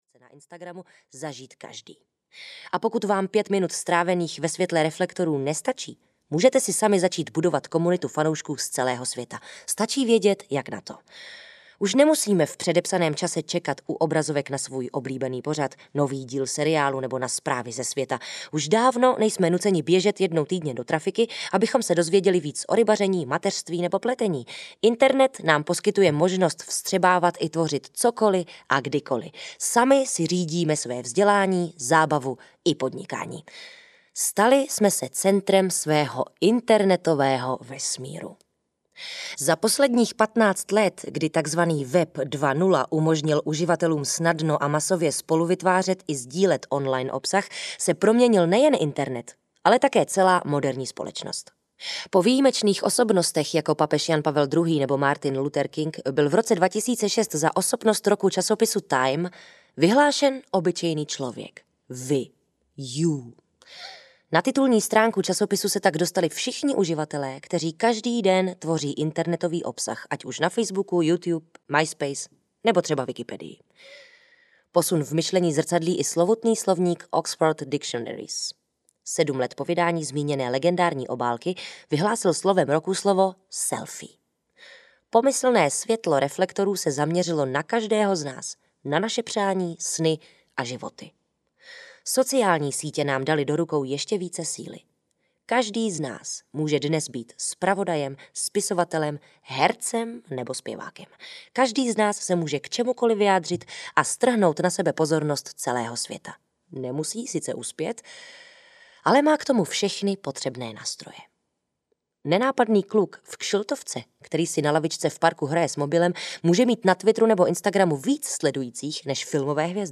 Jak na sítě audiokniha
Ukázka z knihy